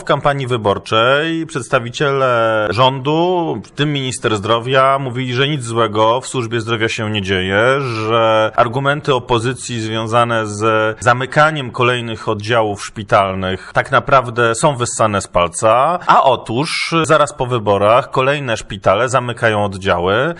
Jak mówi Przewodniczący Komisji Zdrowia i Kultury Fizycznej w Sejmiku Województwa Mazowieckiego, Krzysztof Strzałkowski taka sytuacja zagraża bezpieczeństwu zdrowotnemu mieszkańców Mazowsza.